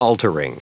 Prononciation du mot : altering